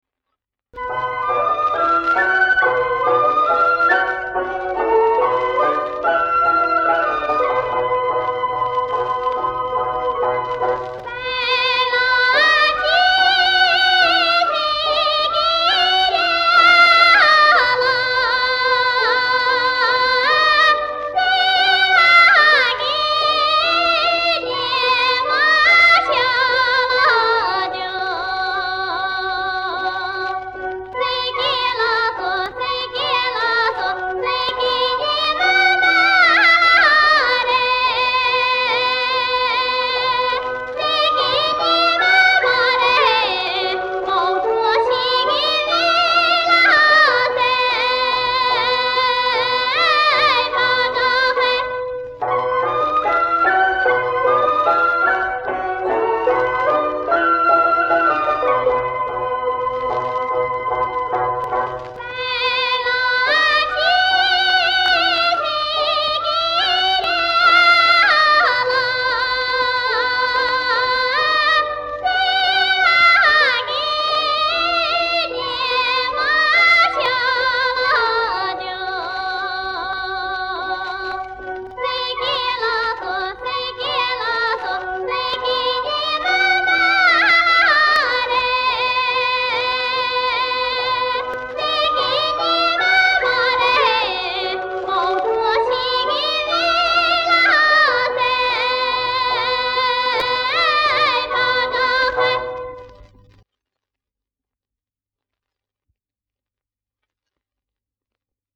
藏族民歌